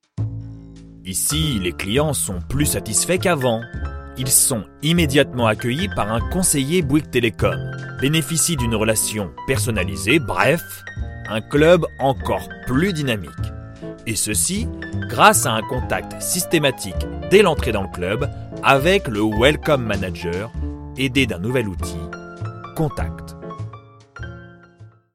Bouygues : voix grave naturelle